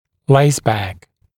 [‘leɪsbæk][‘лэйсбэк]связка типа laceback, обратная лигатурная подвязка «восьмеркой»